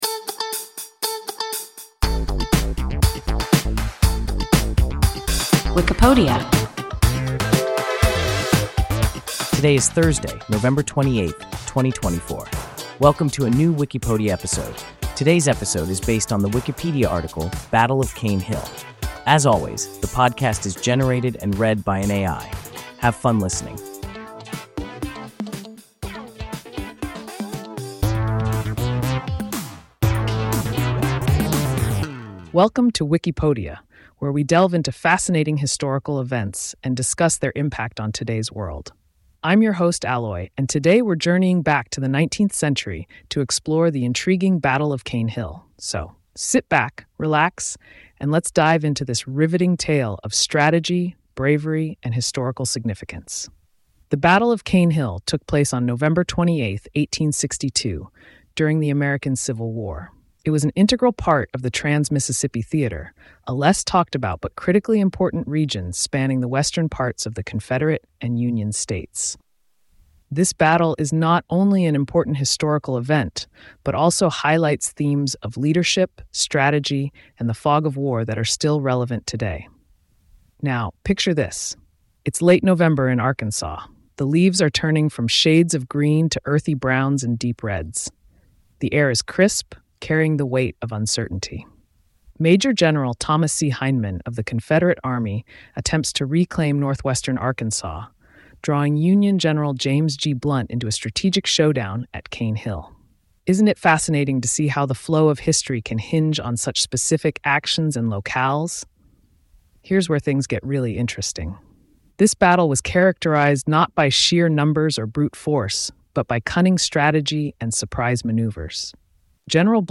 Battle of Cane Hill – WIKIPODIA – ein KI Podcast